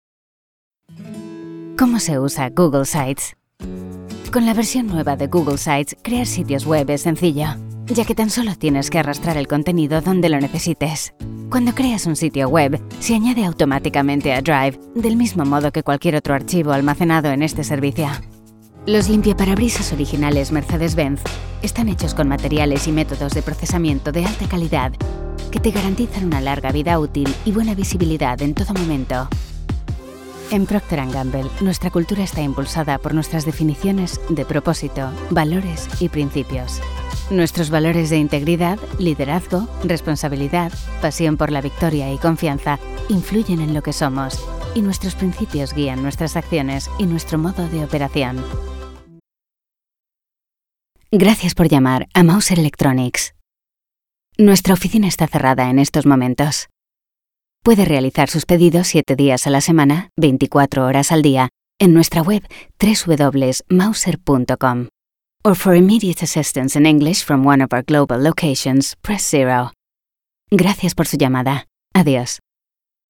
My voice has often been described as being versatile,cosmopolitan, intelligent and sincere, maintaining a fine balance between elegance and sensuality.
kastilisch
Sprechprobe: eLearning (Muttersprache):
Sprechprobe: Industrie (Muttersprache):